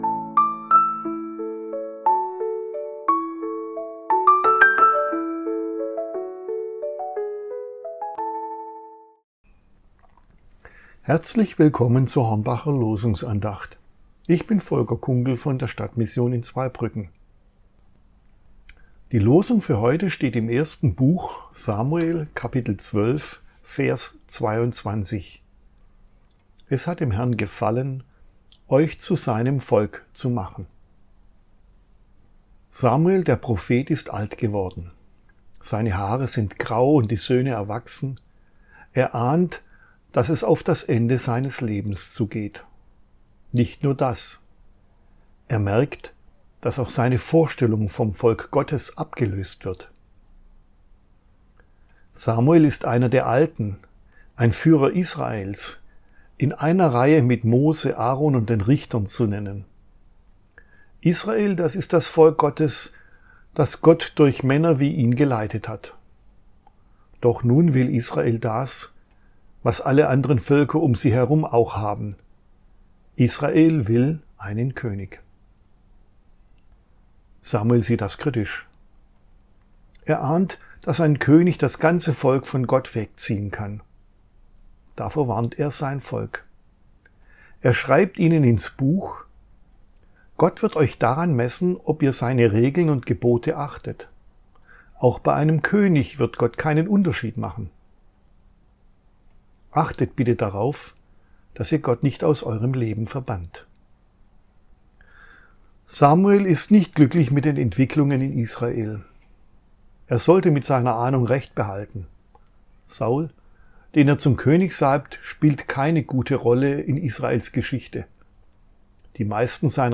Losungsandacht für Dienstag, 09.09.2025